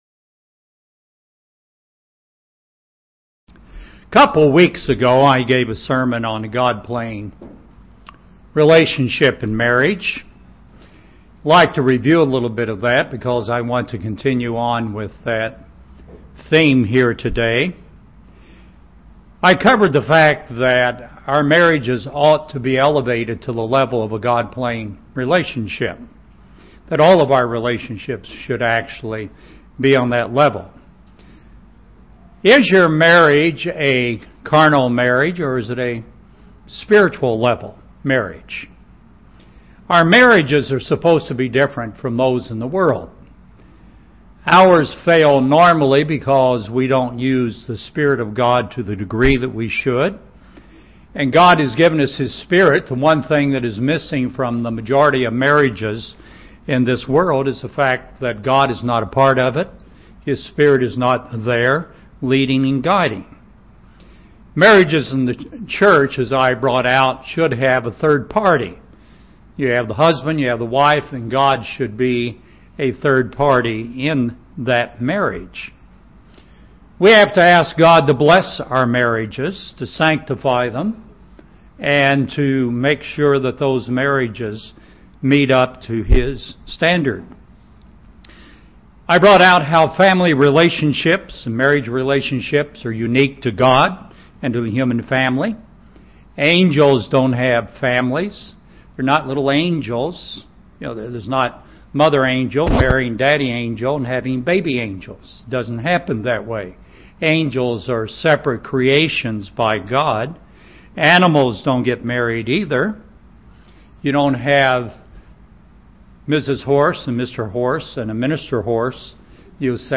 In this sermon, we will focus on the role of a husband.